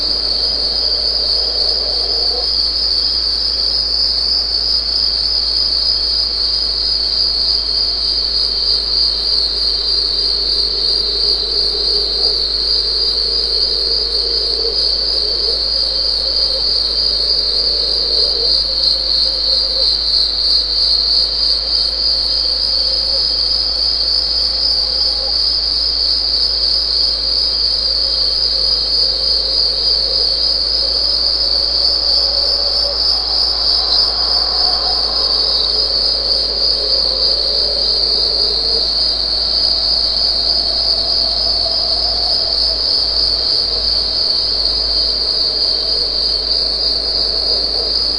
crickets.wav